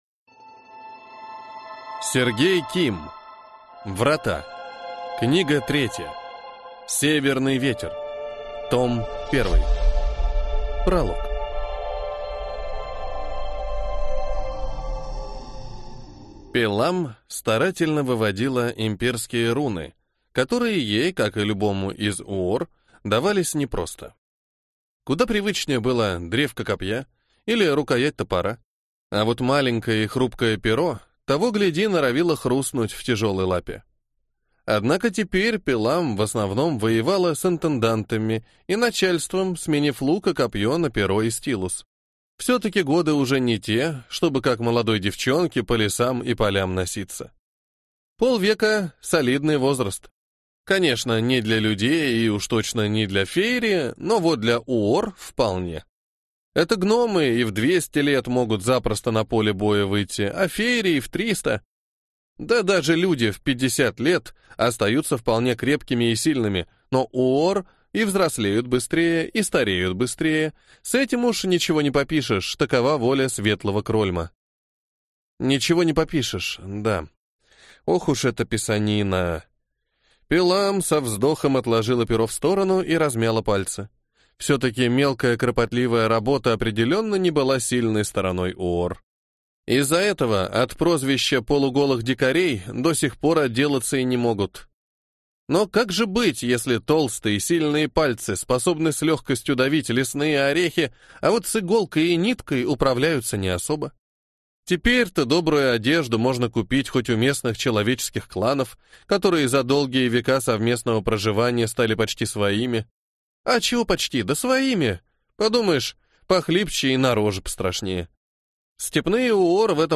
Аудиокнига Врата. Книга 3. Северный ветер. Том 1 | Библиотека аудиокниг